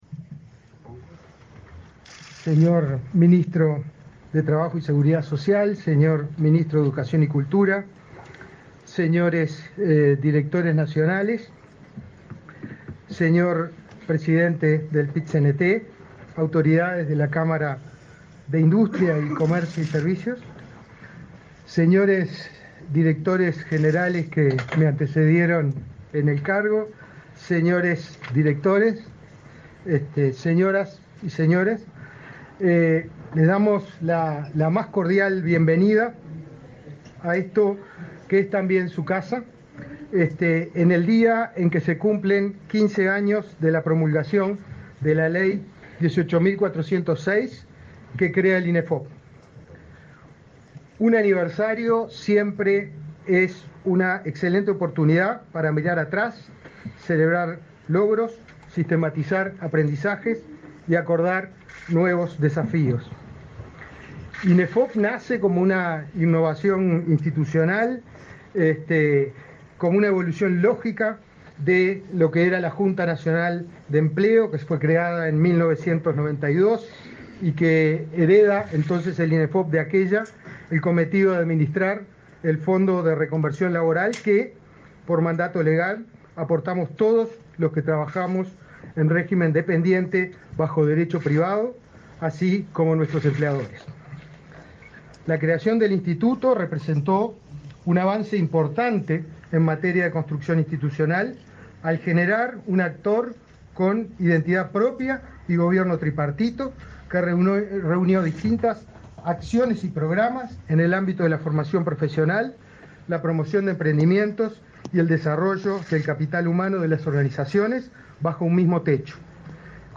Acto por el aniversario del INEFOP
Acto por el aniversario del INEFOP 24/10/2023 Compartir Facebook X Copiar enlace WhatsApp LinkedIn El Instituto Nacional de Formación Profesional (Inefop) festejó, este 23 de octubre, su 15.° aniversario. Participaron en el evento los ministros de Trabajo y Seguridad Social, Pablo Mieres, y de Educación y Cultura, Pablo da Silveira, así como el director general del instituto, Pablo Darscht.